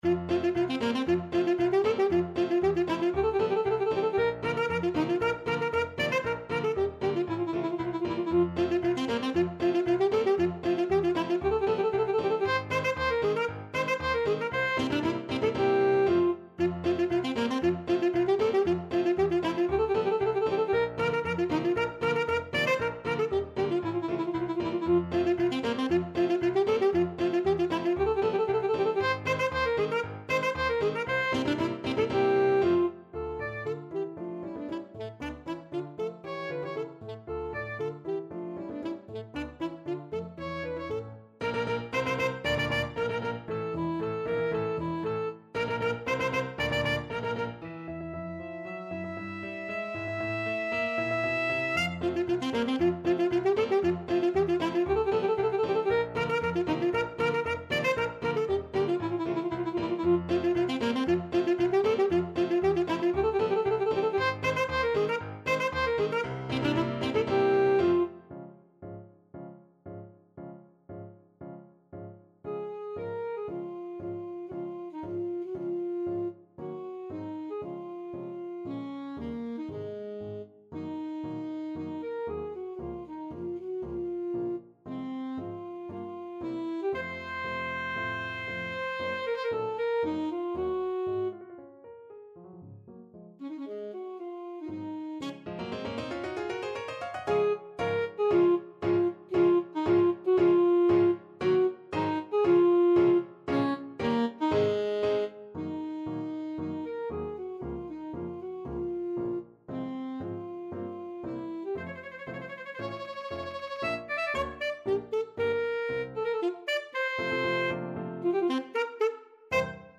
Alto Saxophone
2/4 (View more 2/4 Music)
F major (Sounding Pitch) D major (Alto Saxophone in Eb) (View more F major Music for Saxophone )
Allegro giocoso =116 (View more music marked Allegro giocoso)
Classical (View more Classical Saxophone Music)
carmen_overture_ASAX.mp3